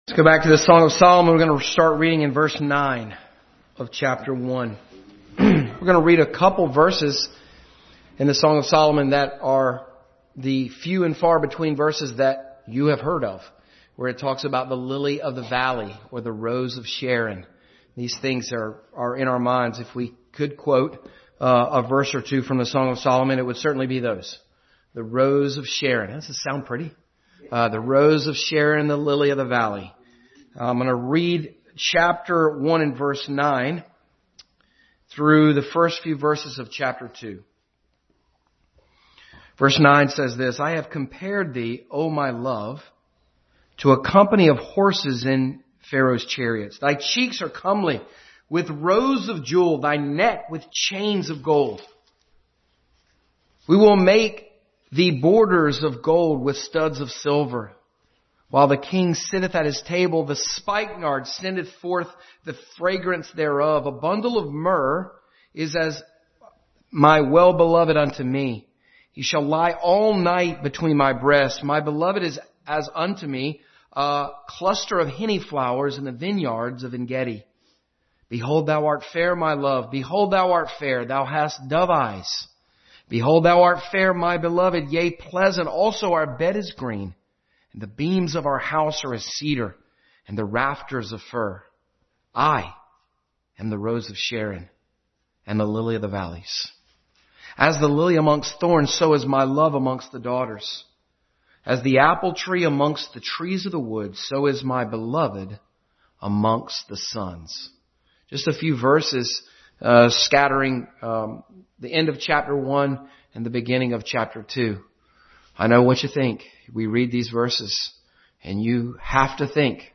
Song of Solomon 1:9-2:3 Passage: Song of Solomon 1:9-2:3, Genesis 41:39-44, Daniel 5:16 Service Type: Family Bible Hour